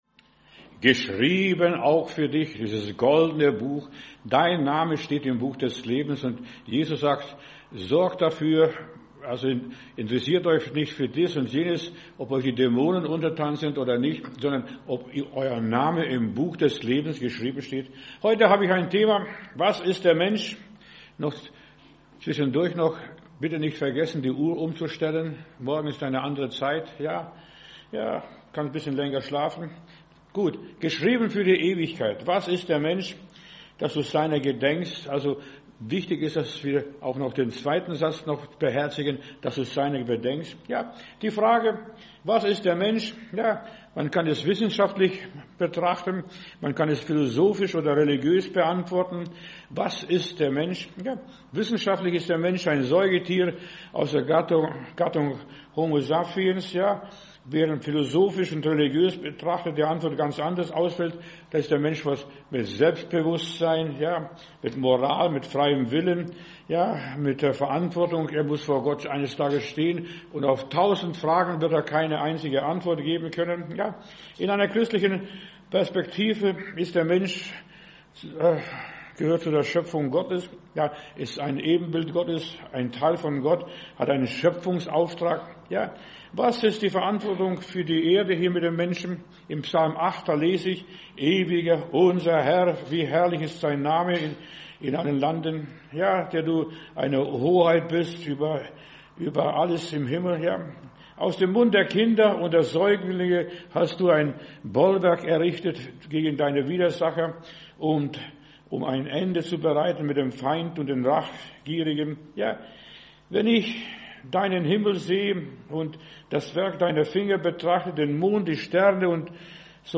Wer uns unterstützen möchte, kann dies hier tun: ♥ Spenden ♥ Predigt herunterladen: Audio 2025-10-25 Was ist der Mensch?